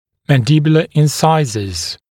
[ˌmæn’dɪbjulə ɪn’saɪzəz][ˌмэн’дибйулэ ин’сайзэз]резцы нижней челюсти